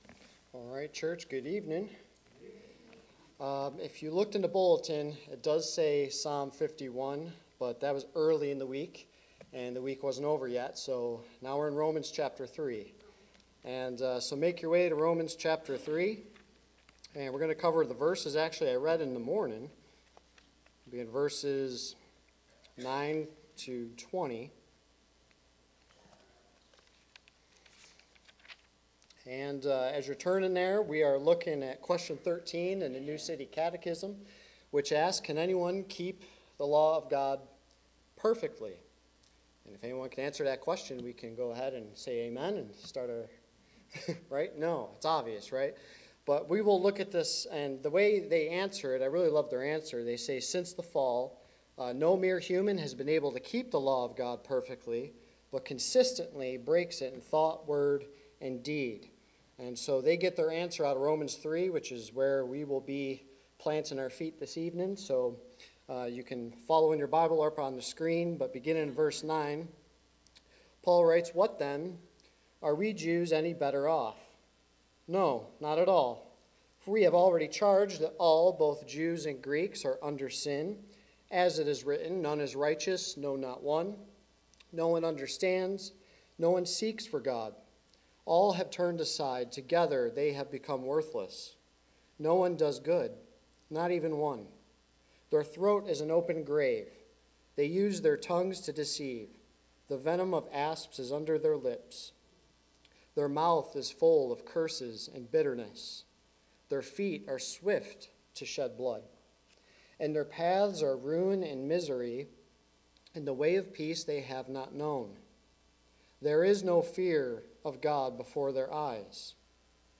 Sermons | Lake Athens Baptist Church